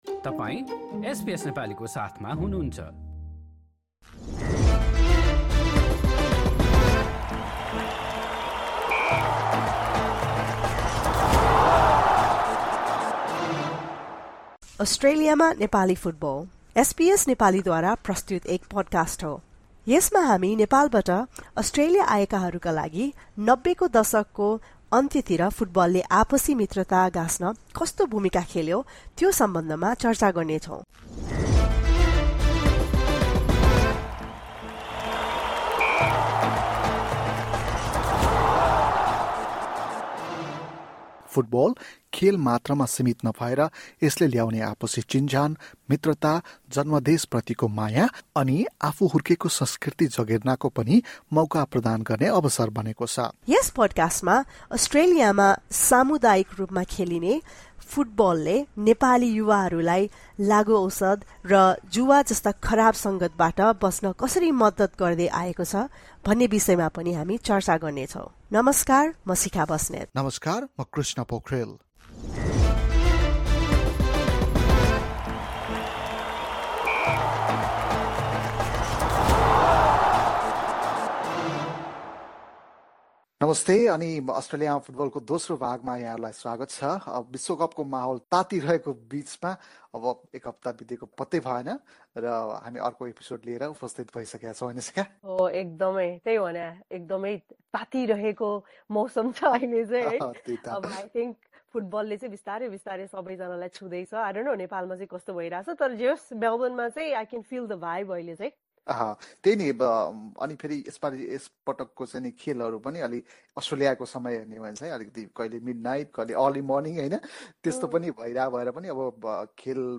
نشرة اخبار الصباح 6/12/2022